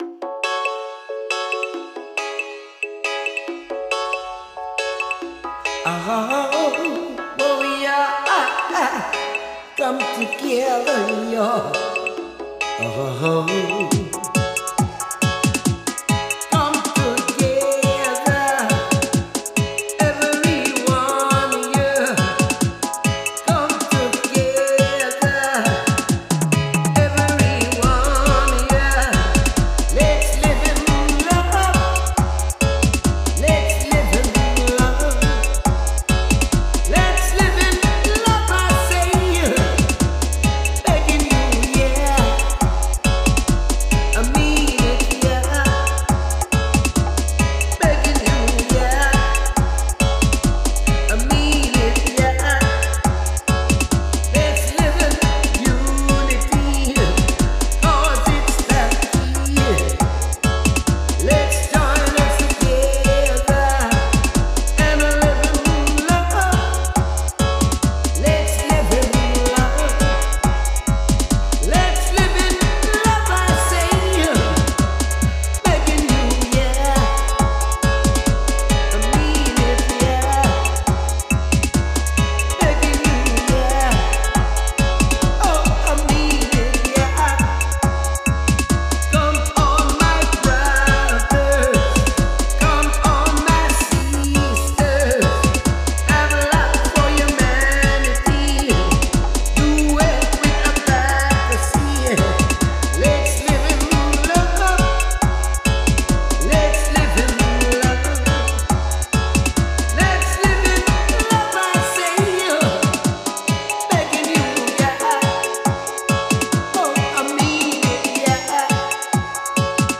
DUB Production